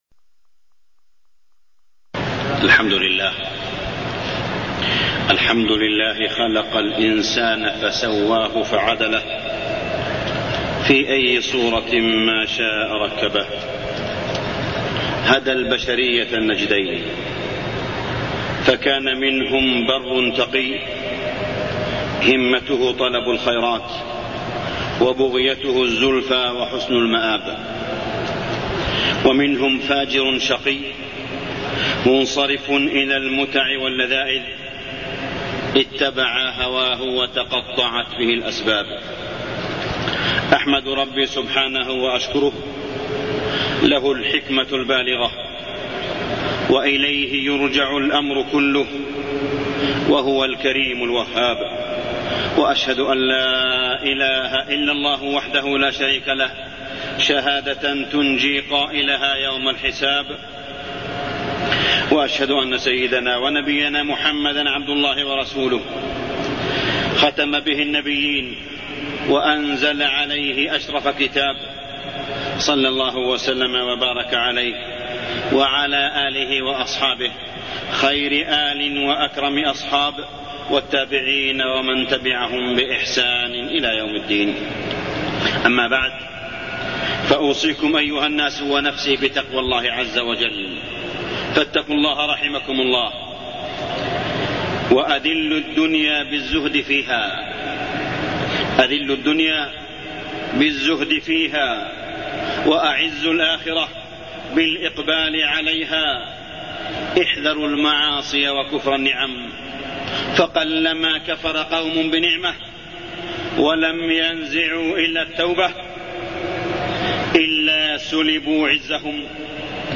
تاريخ النشر ١٦ محرم ١٤٢١ هـ المكان: المسجد الحرام الشيخ: معالي الشيخ أ.د. صالح بن عبدالله بن حميد معالي الشيخ أ.د. صالح بن عبدالله بن حميد حقوق الإنسان